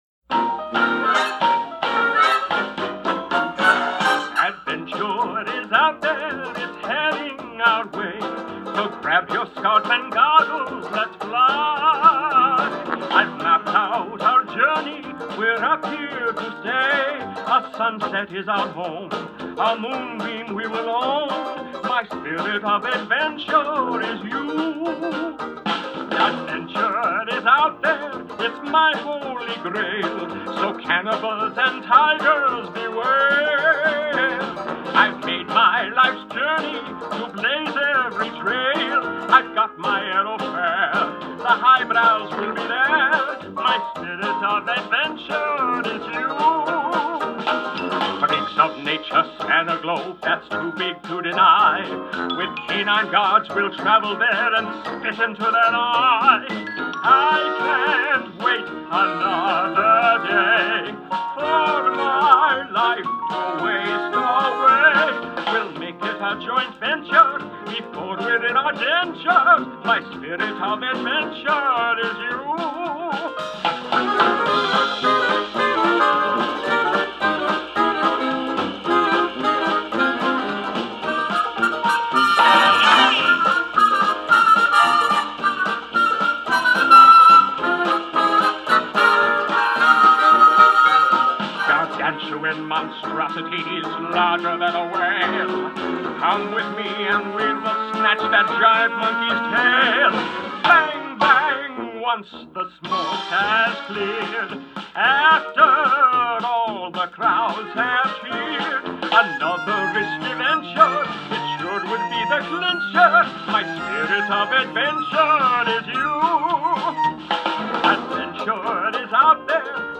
2009   Genre: Soundtrack    Artist